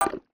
Universal UI SFX / Clicks
UIClick_Smooth Tone Metallic 03.wav